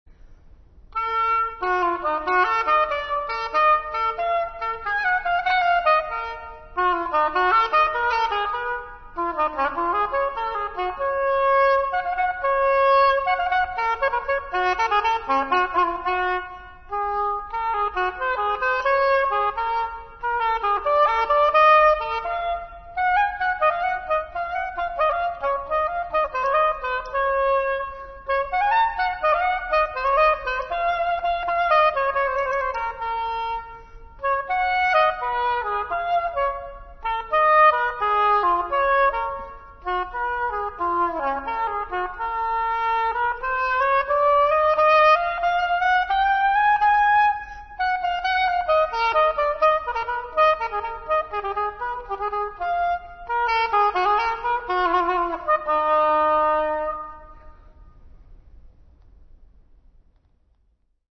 Denner Oboe